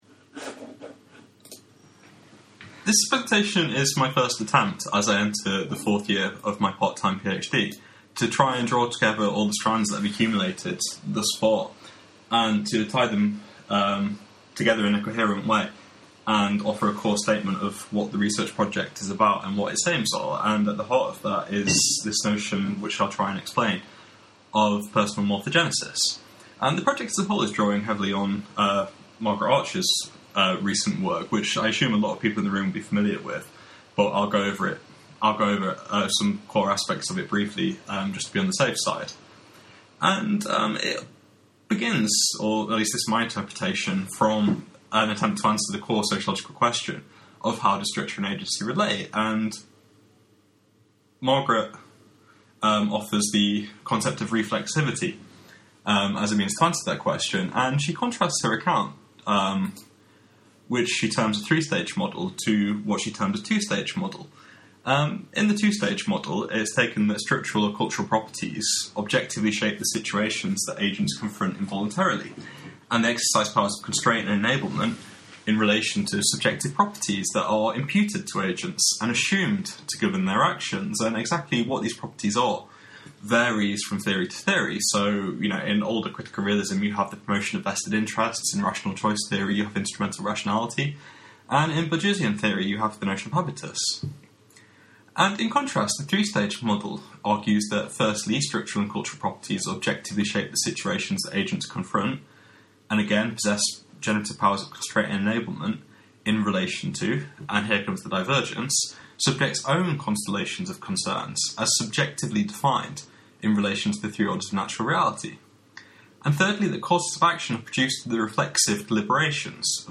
So this is a draft version of a presentation I’m giving at the International Association of Critical Realism conference in Norway on Tuesday.